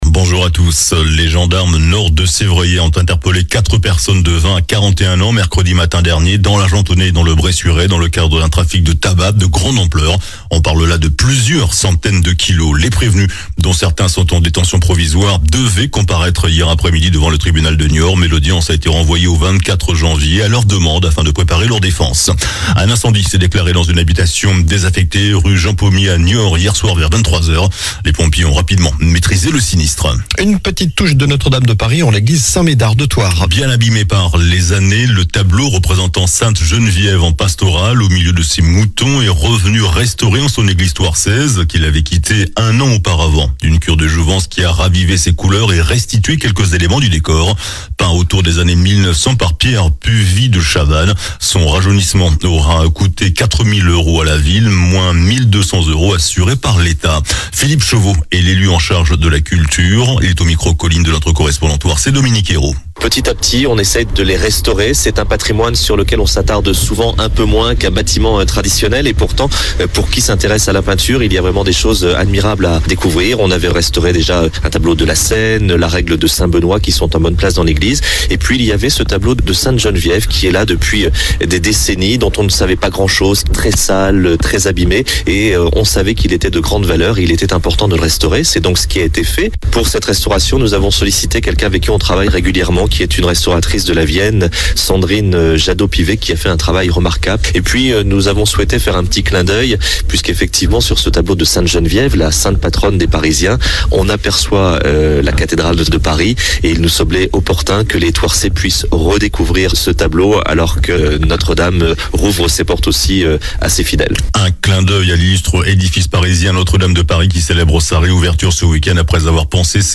JOURNAL DU SAMEDI 07 DECEMBRE